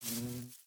Minecraft Version Minecraft Version snapshot Latest Release | Latest Snapshot snapshot / assets / minecraft / sounds / mob / bee / pollinate3.ogg Compare With Compare With Latest Release | Latest Snapshot
pollinate3.ogg